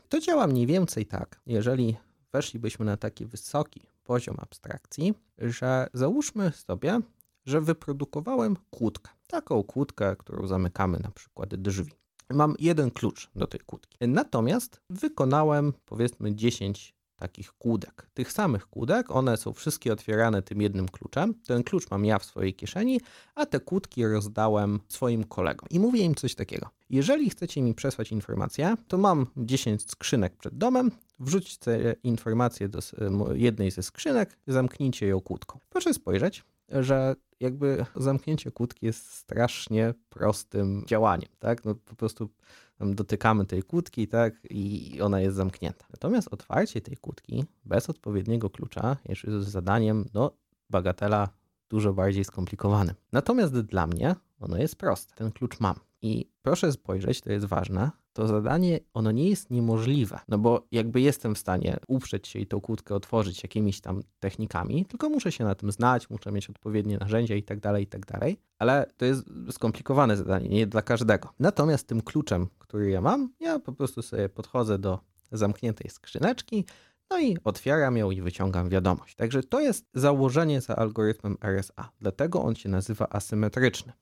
W jaki sposób działa algorytm RSA? Matematyk obrazuje to w prosty sposób.